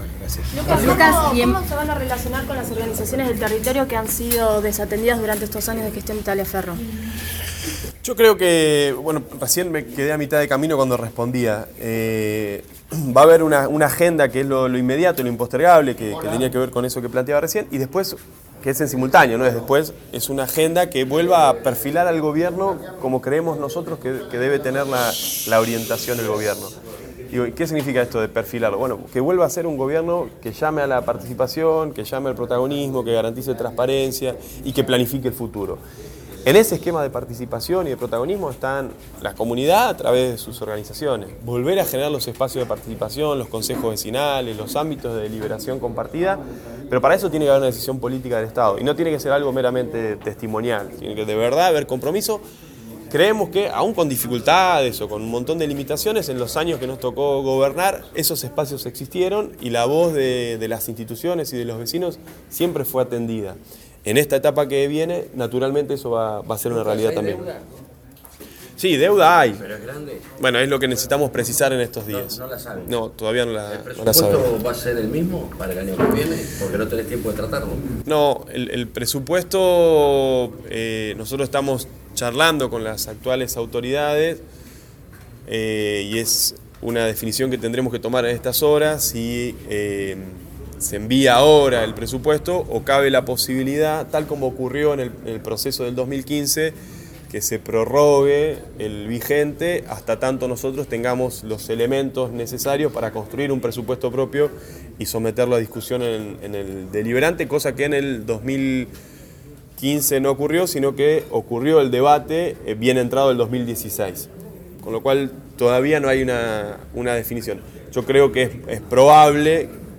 Mundo ciego y FM oeste estuvieron presentes en  la primera conferencia oficial del Intendente  electo por la mayoría del pueblo de Morón.